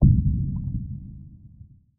Underwater Explosion 2.wav